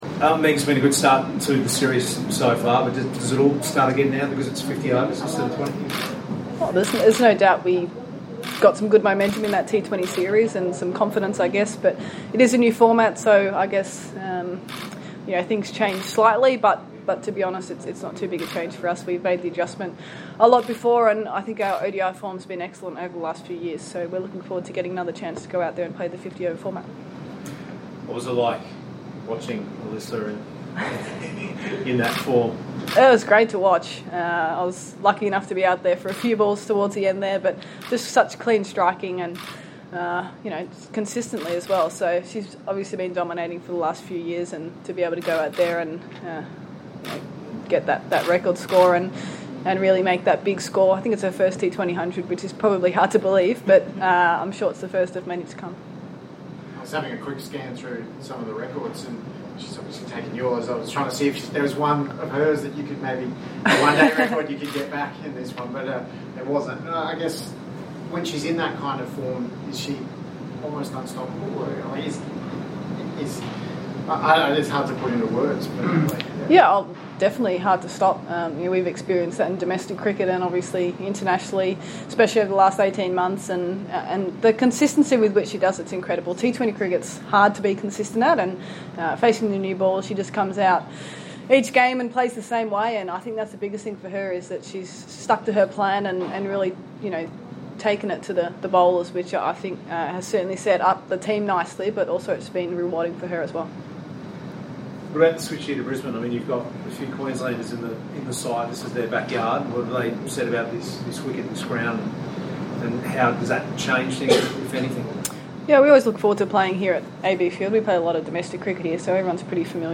Australia captain Meg Lanning spoke to media at a press conference at Allan Border Field in Brisbane ahead of the opening match of the Commonwealth Bank Women's One-Day International Series between Australia and Sri Lanka starting tomorrow.